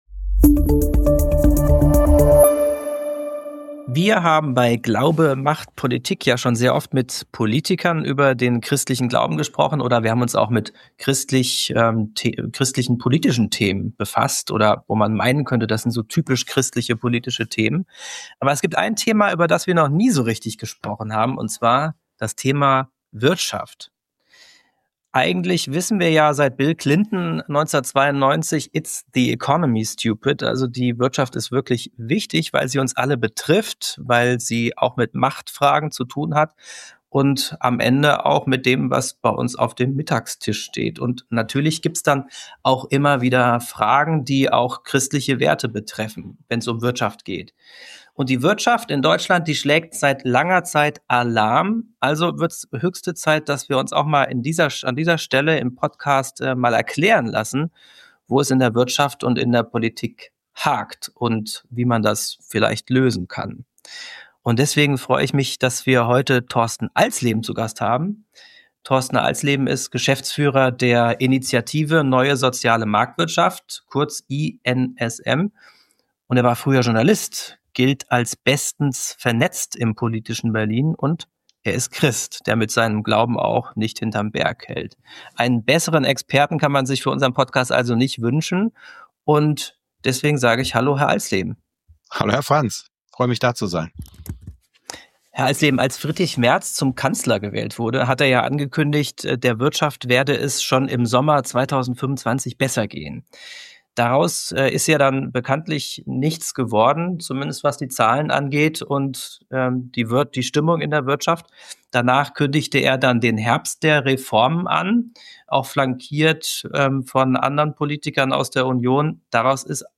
Ein Gespräch über wirtschaftliche Realitäten, politische Blockaden – und die Frage, wie ein neuer Aufbruch gelingen kann.